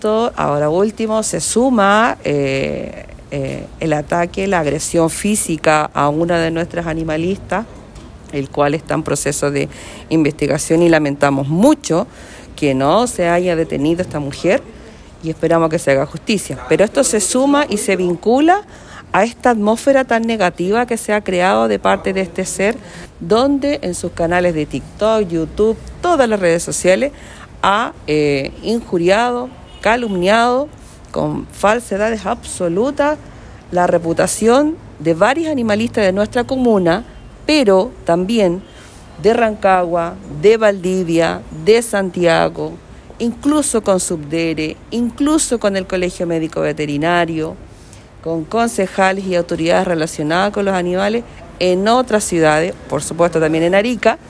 actualidad Entrevista Local Medioambiente